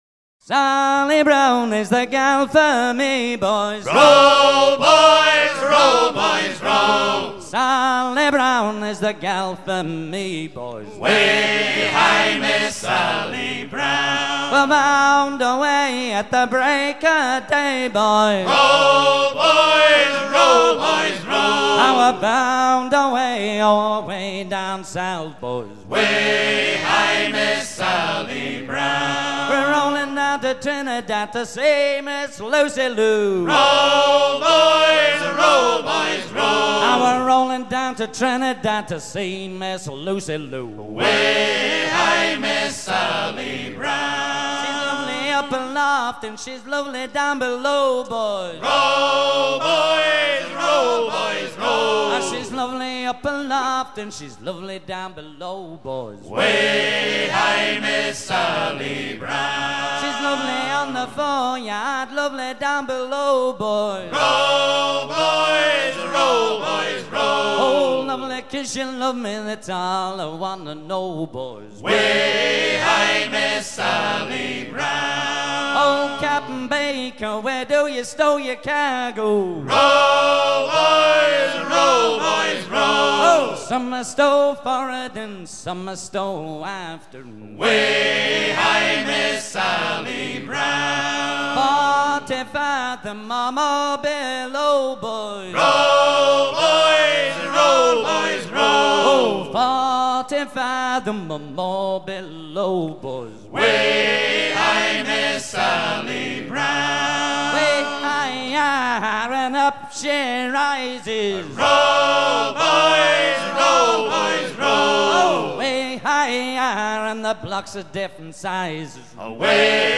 Ce shanty semble originaire de la Jamaïque
à hisser main sur main
Genre laisse
Pièce musicale éditée